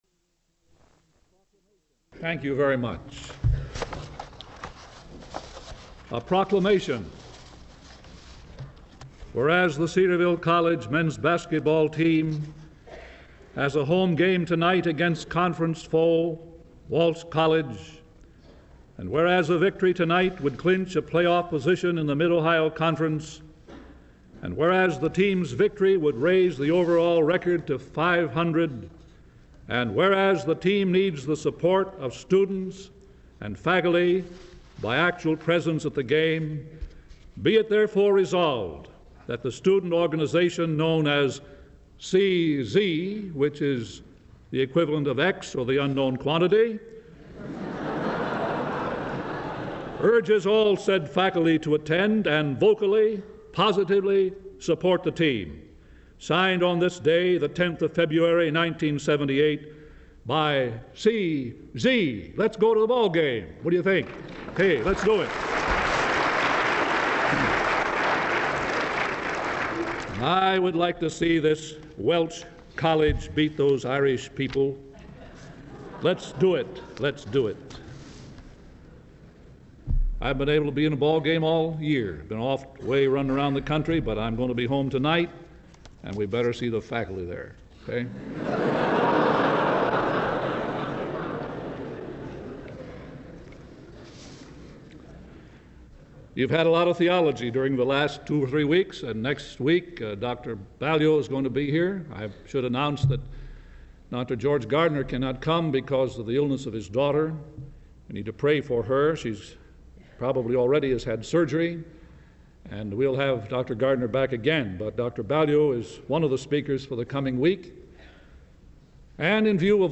Collection of State of the College and State of the University addresses given by presidents of Cedarville University, typically during Homecoming Week.
The State of the University address, sometimes referred to as the Homecoming Address, usually occurs in chapel during Homecoming Week.